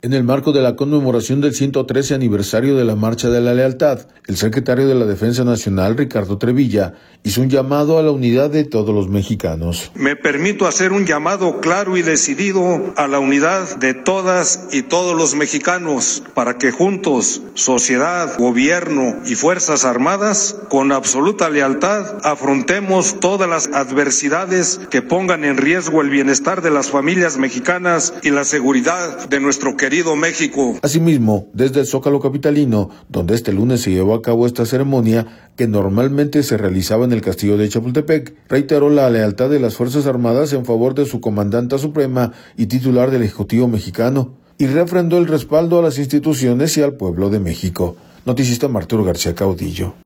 En el marco de la conmemoración del 113 aniversario de la Marcha de la Lealtad, el secretario de la Defensa Nacional, Ricardo Trevilla, hizo un llamado a la unidad de todos los mexicanos.